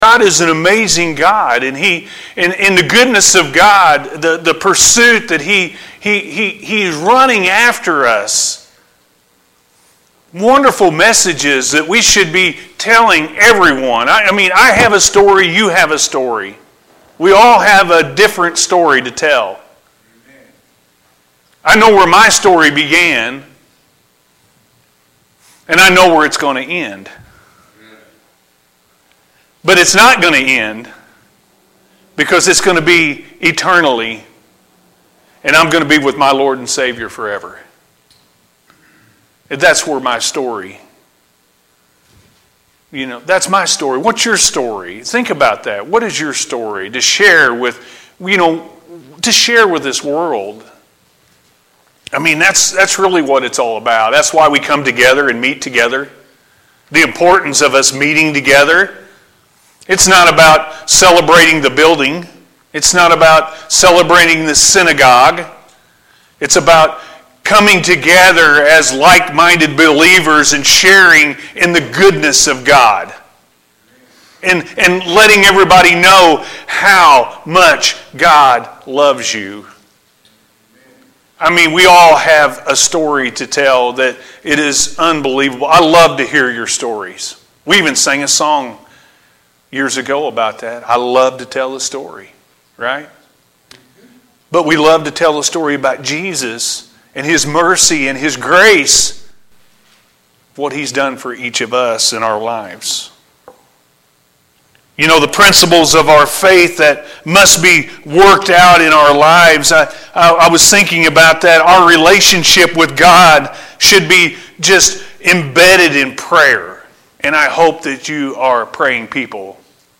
What Is Your Story To Share With The World?-A.M. Service – Anna First Church of the Nazarene